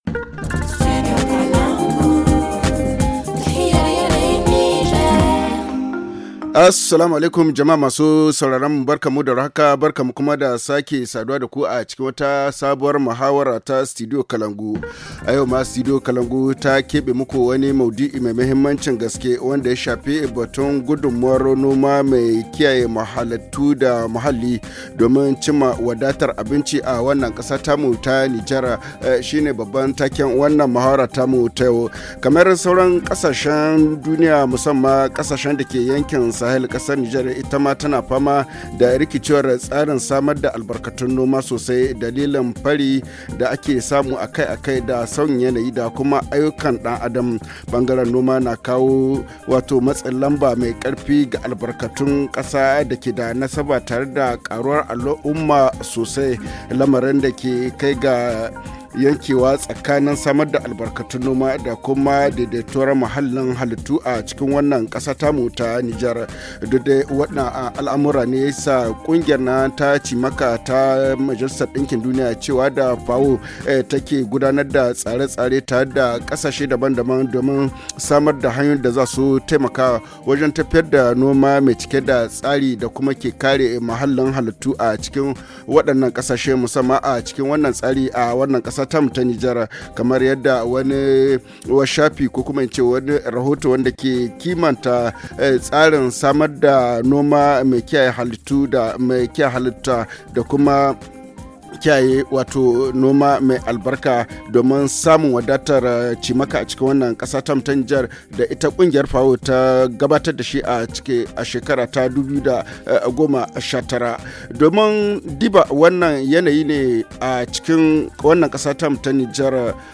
de l’ONG Karkara HA Le forum en haoussa Télécharger le forum ici.